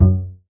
bassattack.ogg